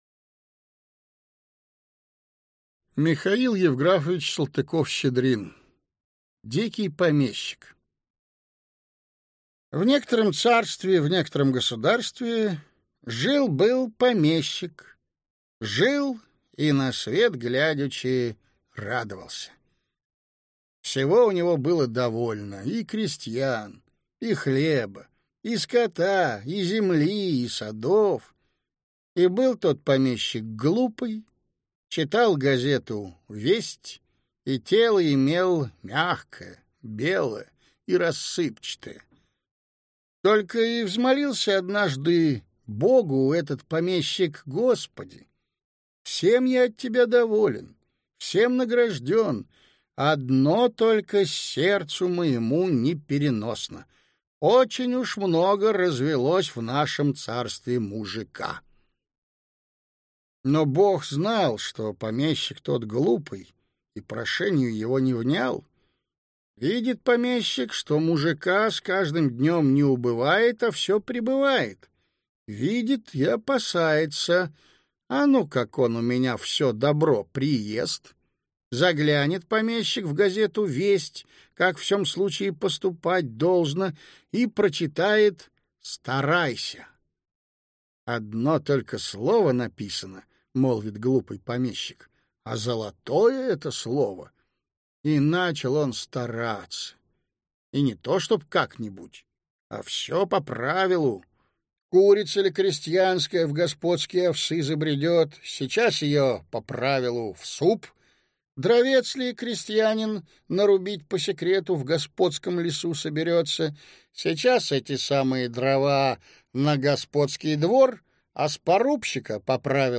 Аудиокнига Дикий помещик | Библиотека аудиокниг